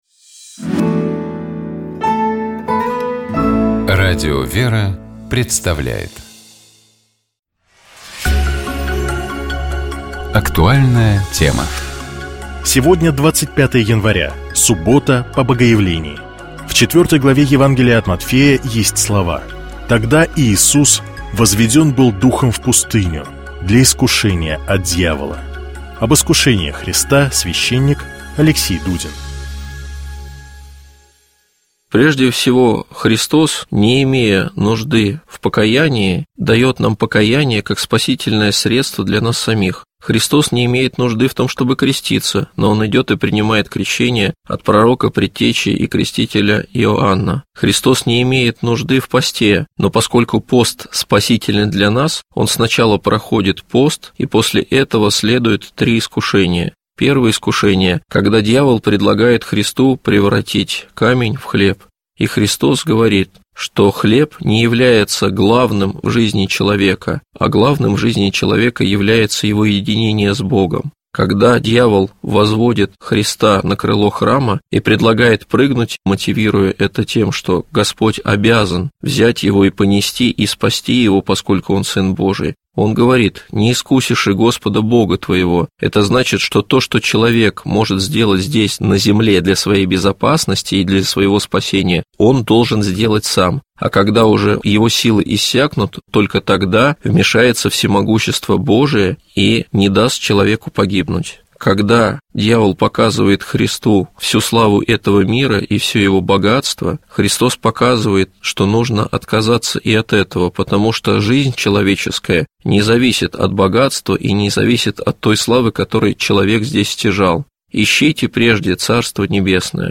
Об этом — новый разговор